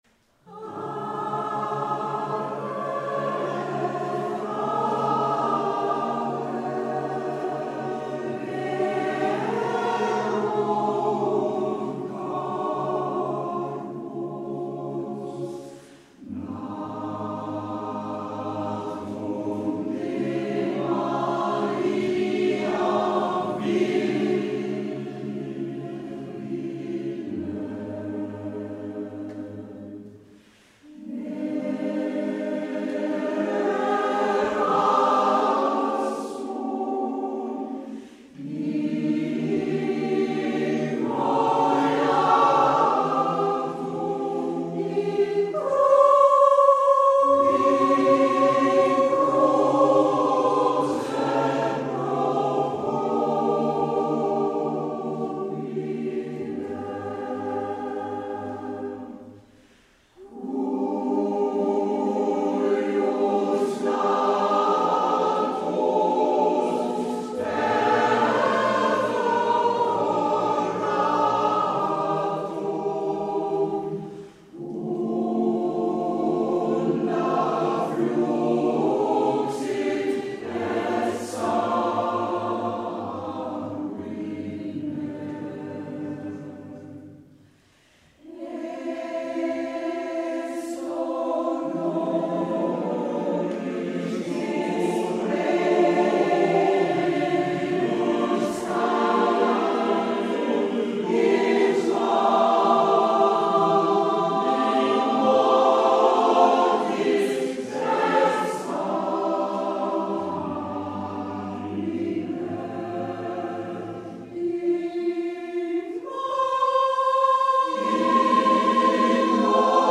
Trauerchor